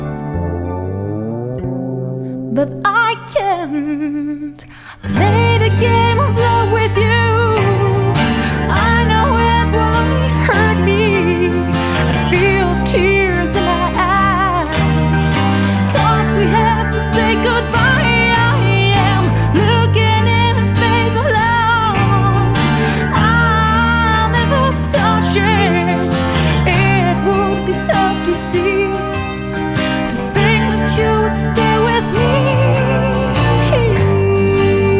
Жанр: Альтернатива
# Alternative